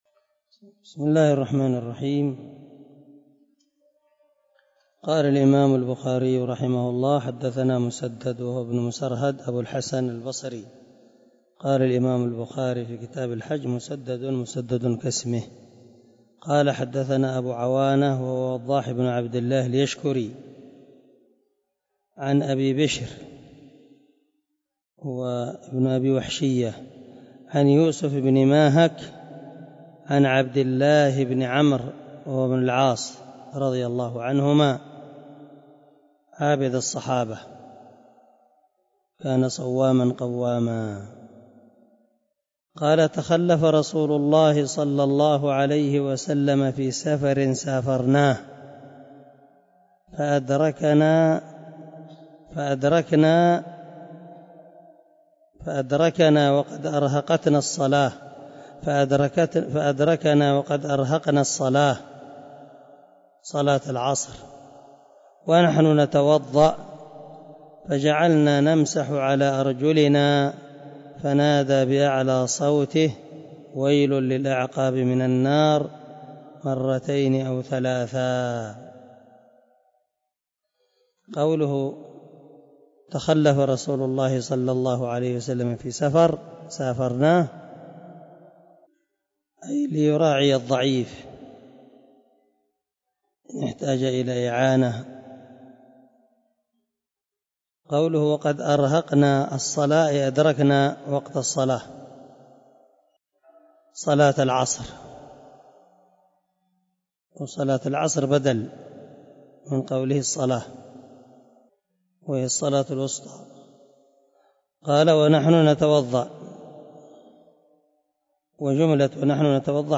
092الدرس 37 من شرح كتاب العلم حديث رقم ( 96 ) من صحيح البخاري